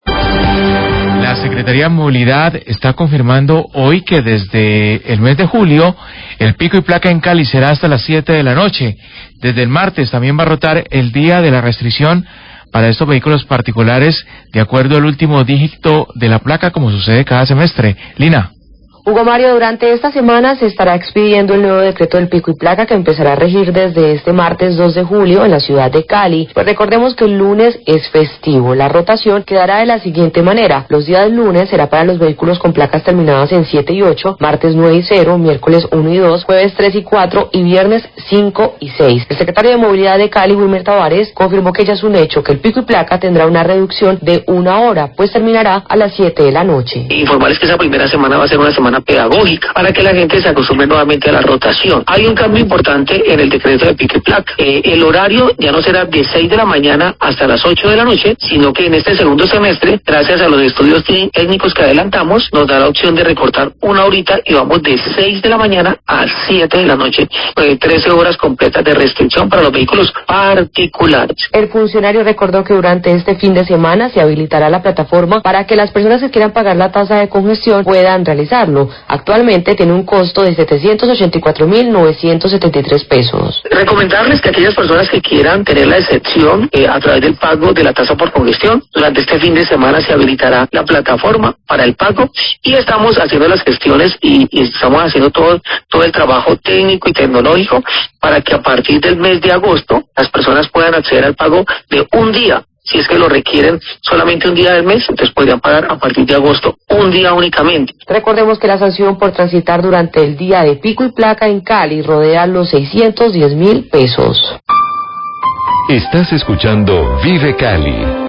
Radio
Esta normativa establece una reducción en el tiempo de la restricción vehicular, que ahora será de 13 horas diarias en lugar de 14, funcionando desde las 6:00 a.m. hasta las 7:00 p.m. El Secretario de Movilidad de Cali, Wilmer Tabares, habla de las novedades que trae este nuevo pico y placa. También habla del pago de la tasa por congestión.